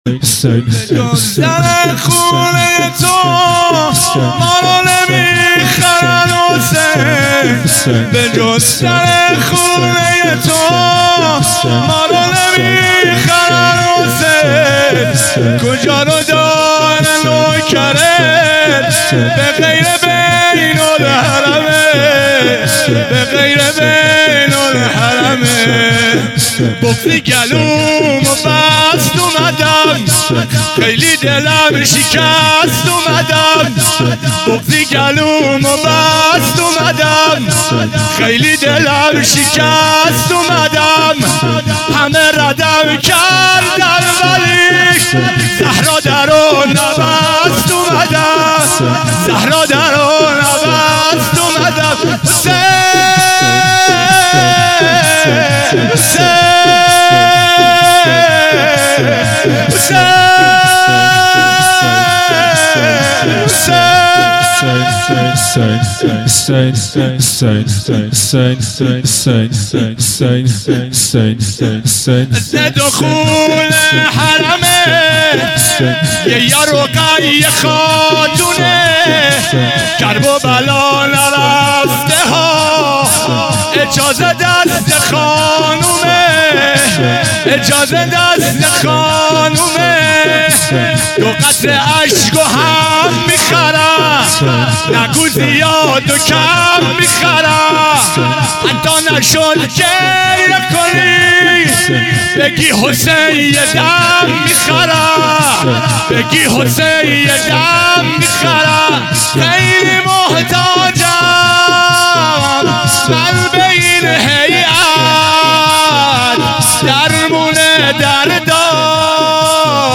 شور | به جز در خوته ی تو
دهه اول مــحـرمالـحـــرام ۱۴۴۱